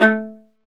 Index of /90_sSampleCDs/Roland - String Master Series/STR_Viola Solo/STR_Vla1 % marc